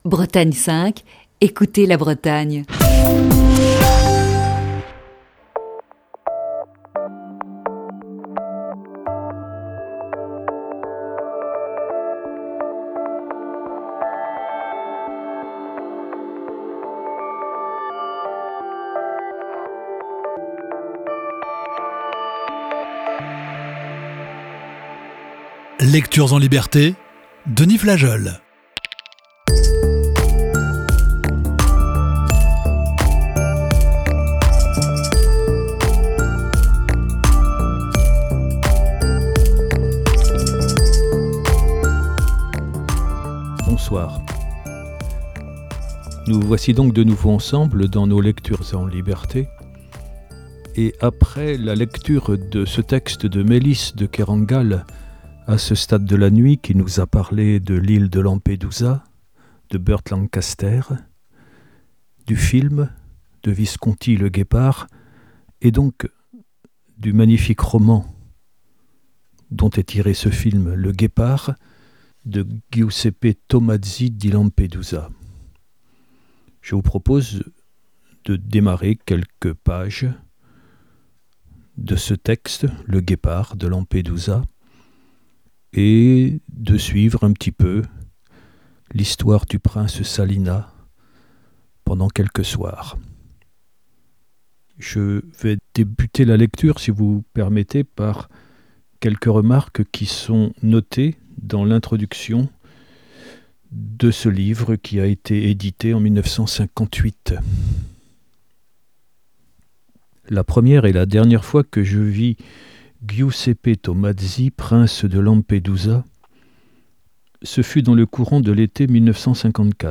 Émission du 21 mai 2020. Ce soir dans Lecture(s) en liberté